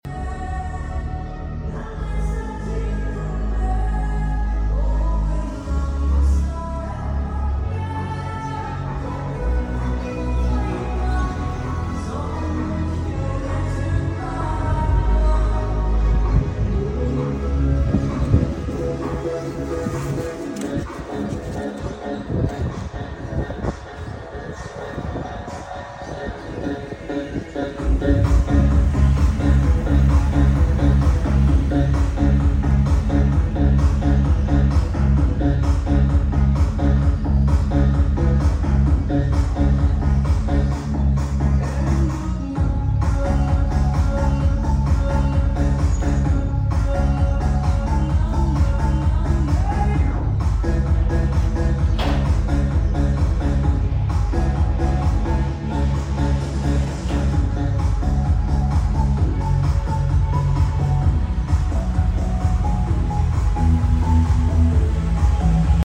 Trưa nghe tiếng nhạc vang sound effects free download